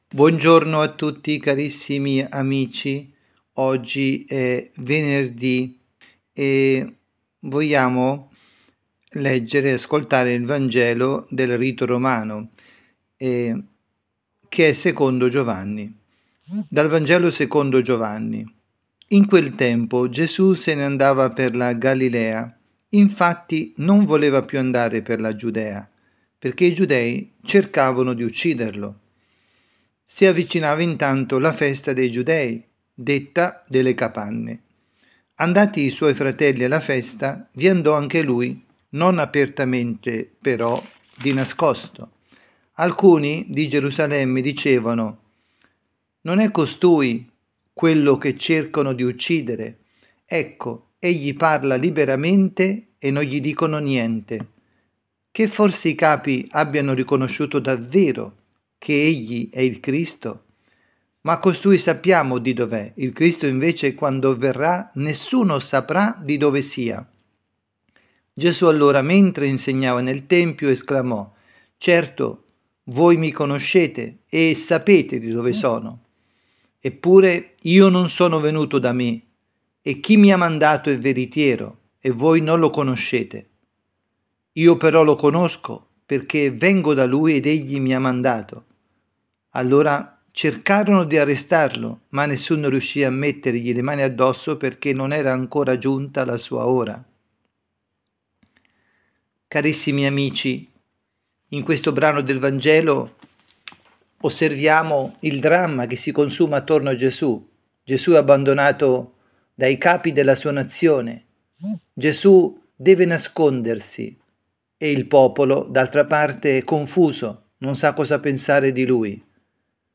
avvisi, Catechesi, Omelie, Quaresima